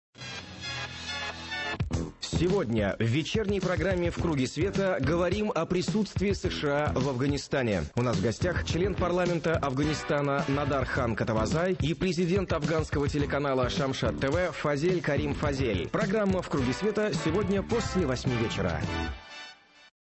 на радио «Эхо Москвы»
Аудио: анонс –